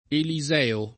eli@$o; sp. eliS%o] pers. m. — sim., in it., i cogn.